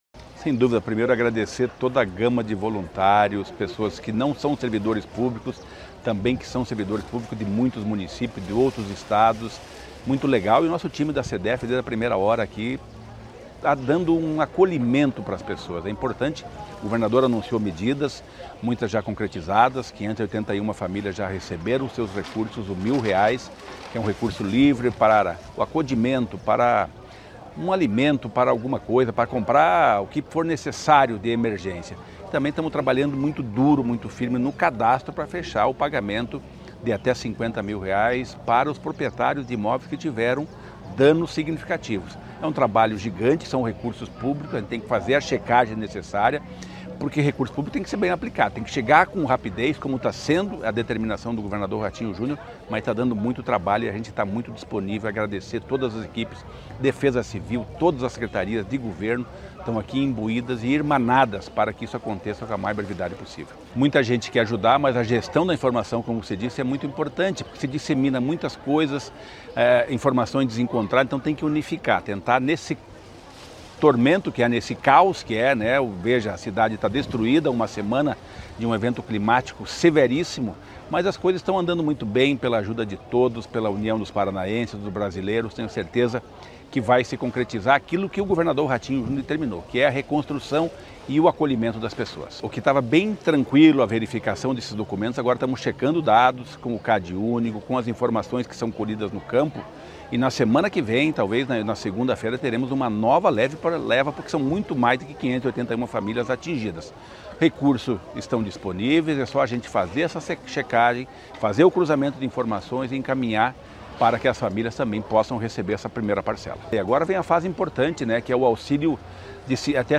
Sonora do secretário do secretário do Desenvolvimento Social e Família, Rogério Carboni, sobre o auxílio emergencial de R$ 1 mil para famílias em Rio Bonito do Iguaçu